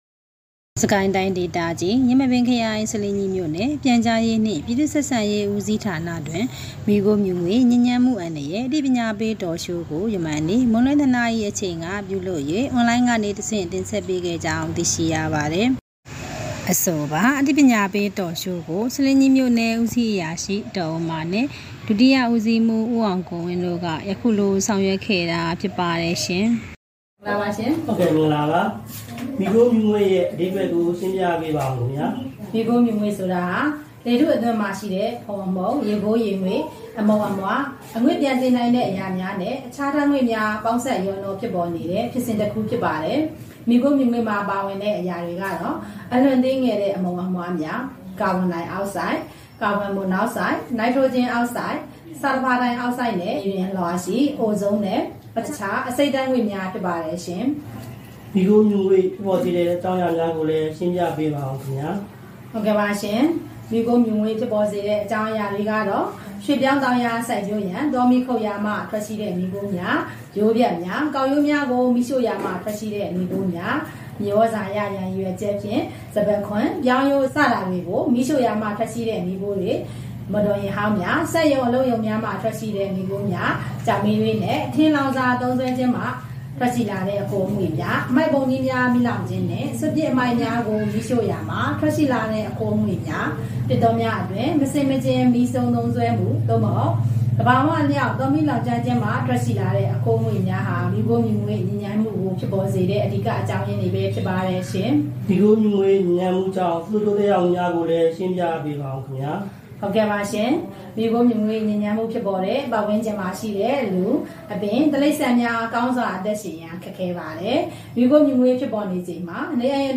ဆားလင်းကြီးတွင် မီးခိုးမြူငွေ့ညစ်ညမ်းမှုအန္တရာယ် အသိပညားပေး Talk Show ဆ...